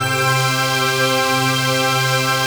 DM PAD2-14.wav